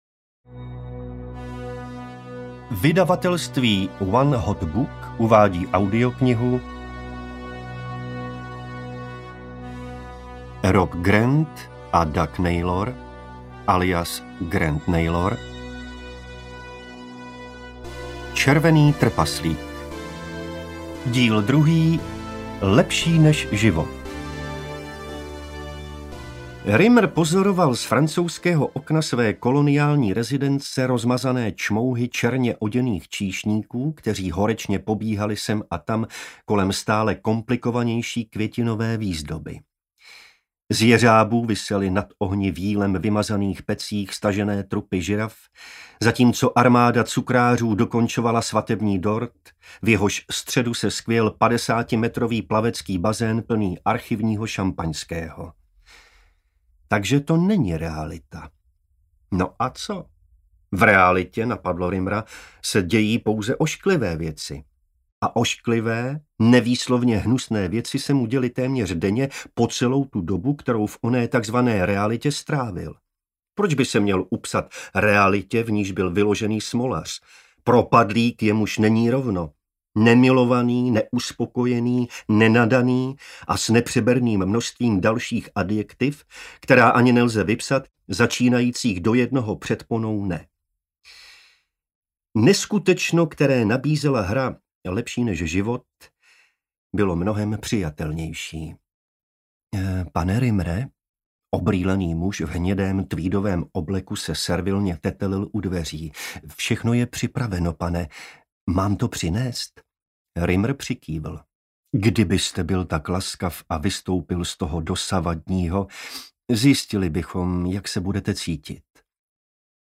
Červený trpaslík 2: Lepší než život audiokniha
Ukázka z knihy